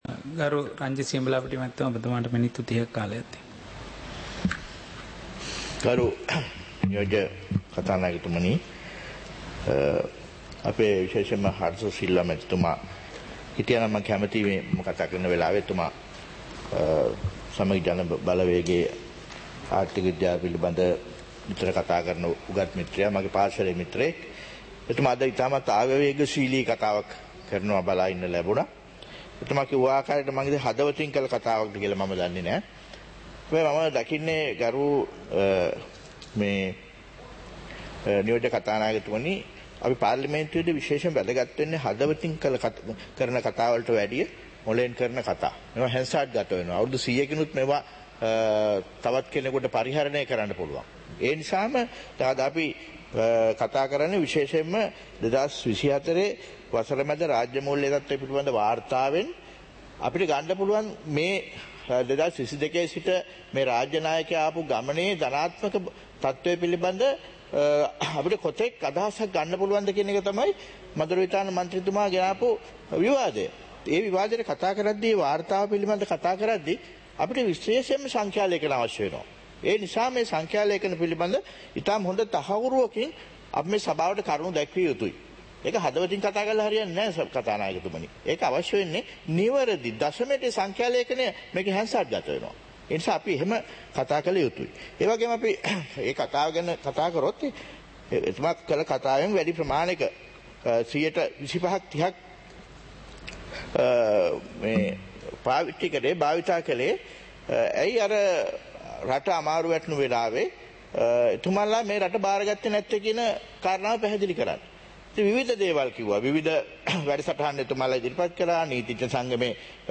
சபை நடவடிக்கைமுறை (2024-08-07)